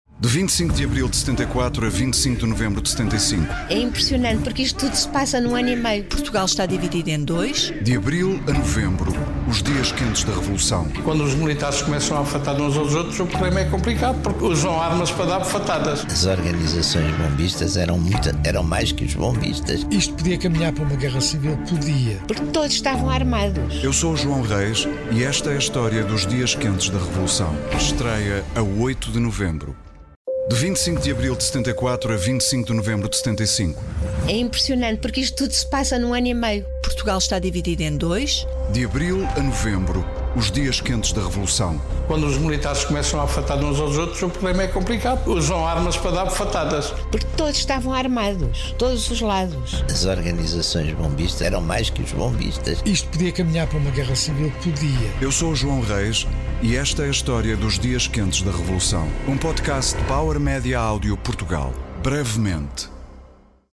Estreia em breve o podcast sobre o que foi o 25 de Novembro.